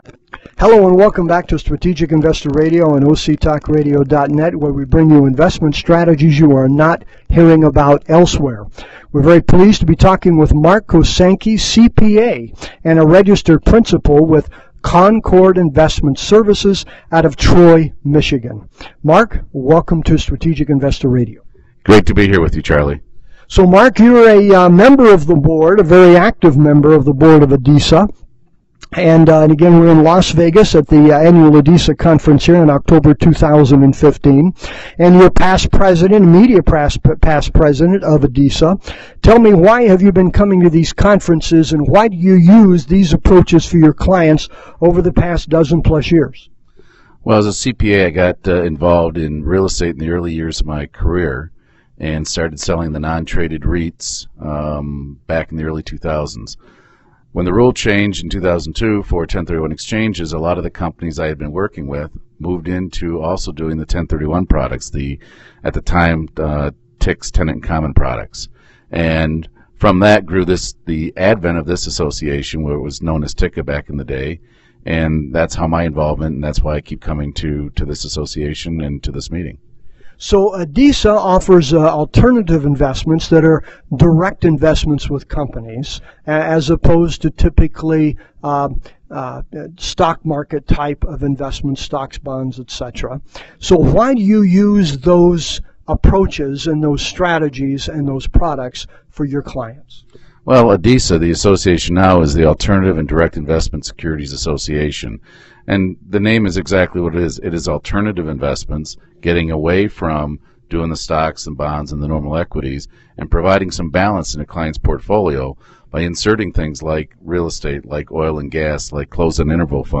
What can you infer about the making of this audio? This interview occurred at the ADISA Conference (the Alternative Direct Investment Securities Assoc)